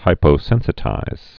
(hīpō-sĕnsĭ-tīz)